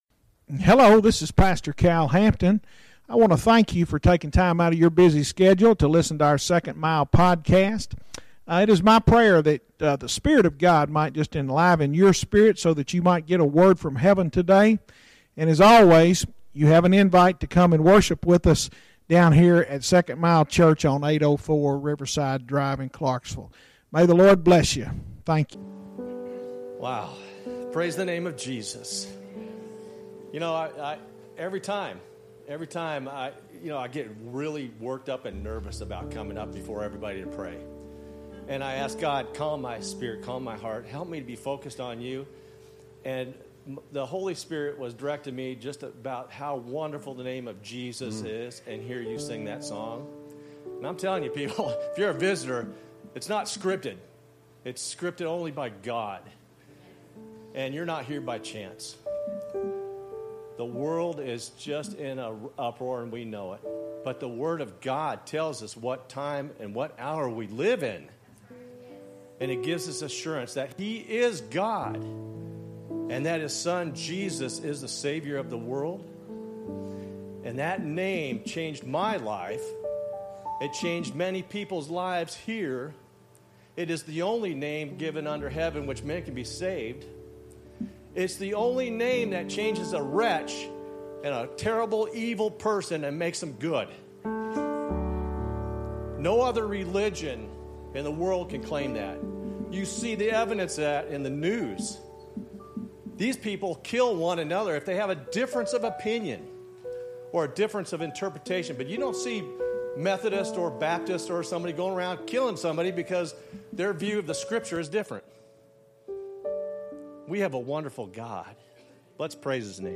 Sermons Archive - 2nd Mile Church